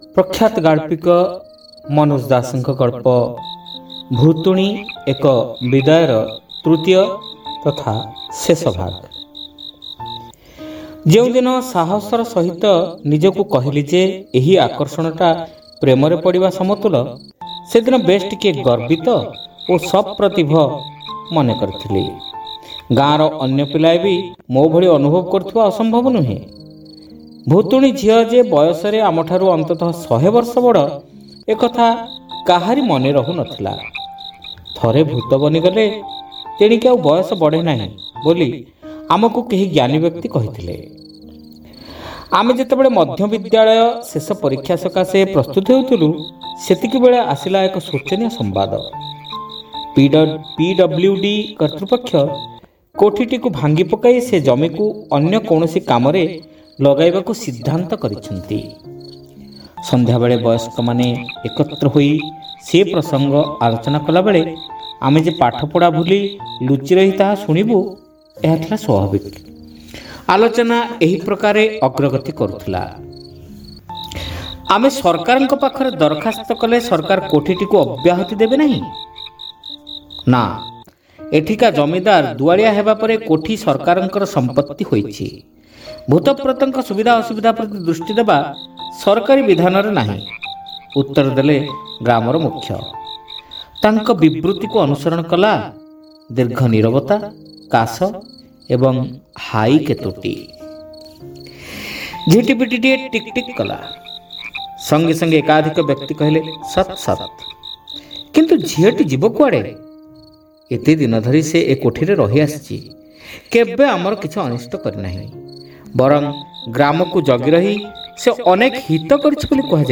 ଶ୍ରାବ୍ୟ ଗଳ୍ପ : ଭୁତୁଣୀ ଏକ ବିଦାୟ (ତୃତୀୟ ଭାଗ)